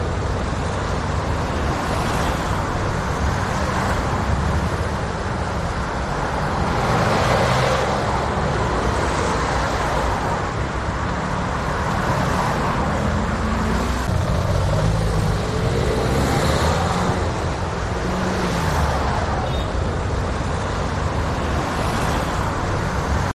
Ruido de tráfico en el centro de Santander